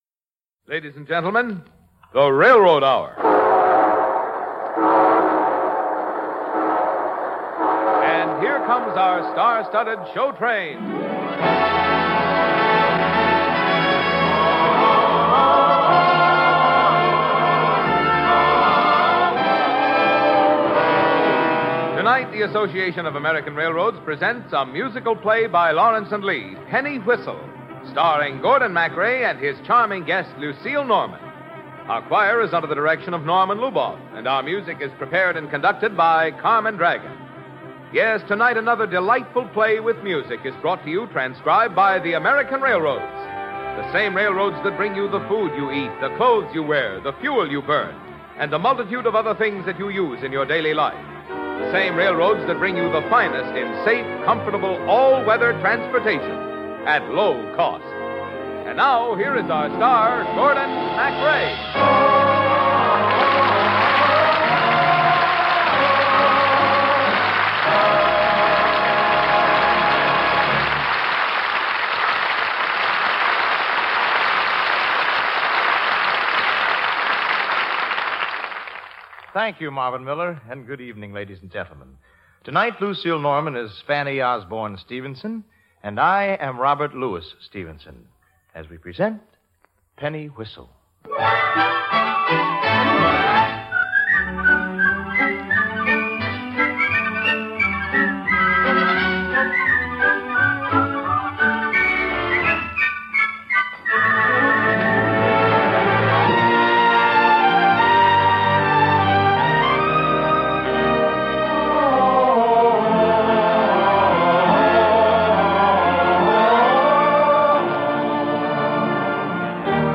radio series that aired musical dramas and comedies